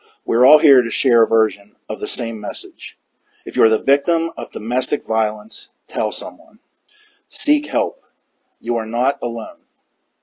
At a news conference today, Indiana Borough Police Chief Justin Schawl released more information on a stabbing last night that nearly killed a female victim.